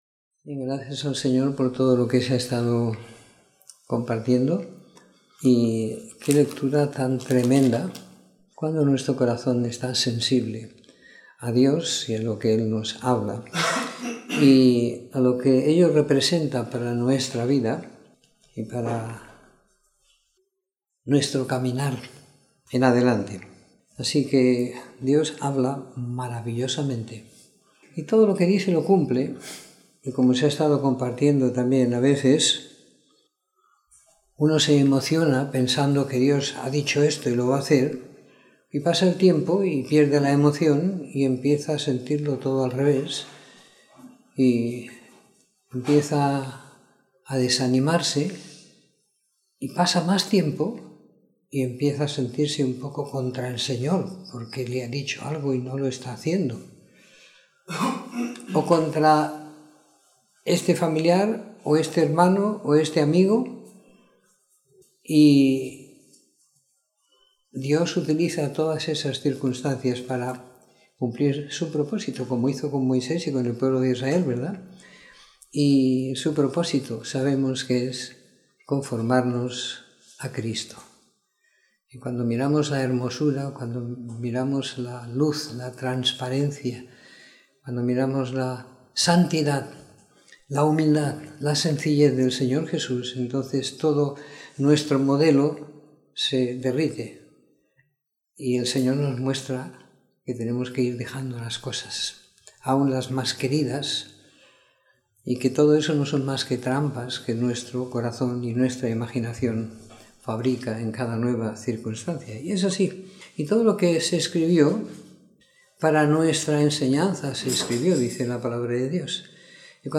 Comentario en el libro de Éxodo del capítulo 1 al 18 siguiendo la lectura programada para cada semana del año que tenemos en la congregación en Sant Pere de Ribes.